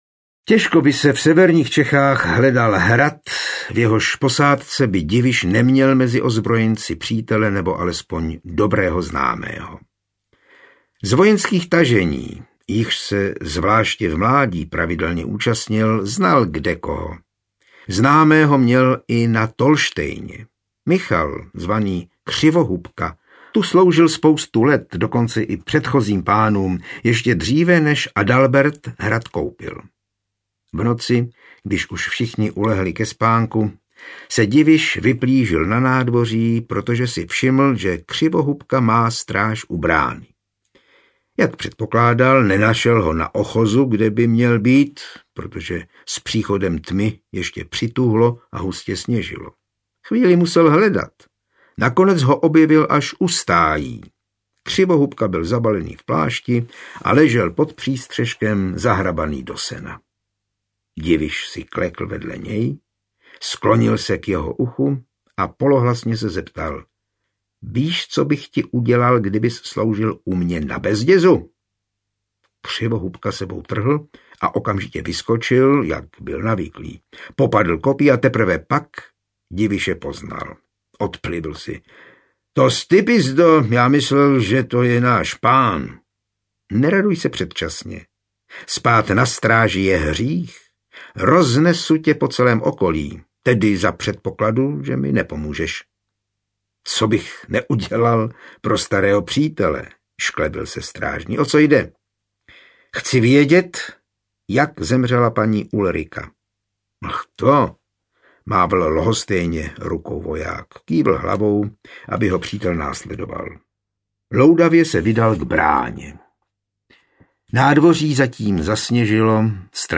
Ukázka z knihy
Audiokniha je bez hudebních předělů a podkresů.